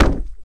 hugeLogPut.wav